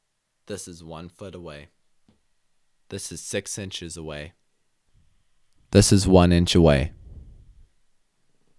Low volume from ATR2100 USB microphone
I have attached a sample of recording from different distances.
But I do think although the voice sounds just fine, you will never make AudioBook standards with very low volume because you also have to hit background noise as one of the three specifications. That’s the quiet FFFFF sound when you stop talking.